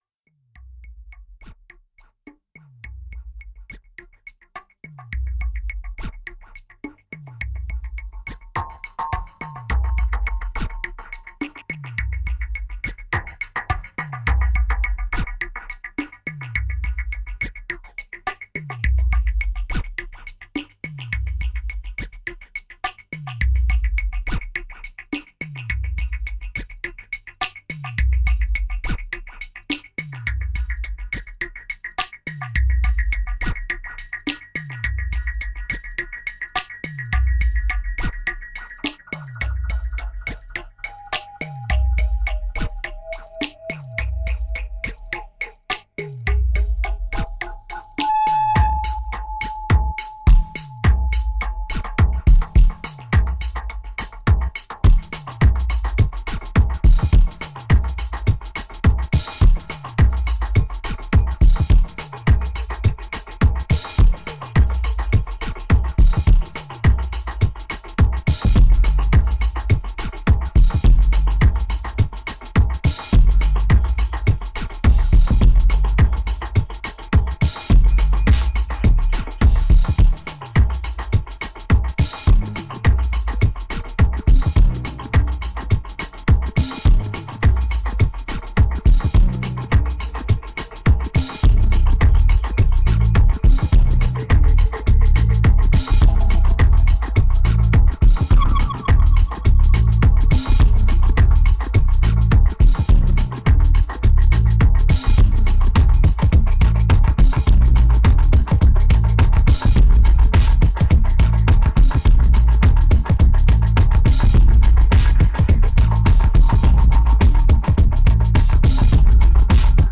1839📈 - 3%🤔 - 105BPM🔊 - 2010-09-06📅 - -120🌟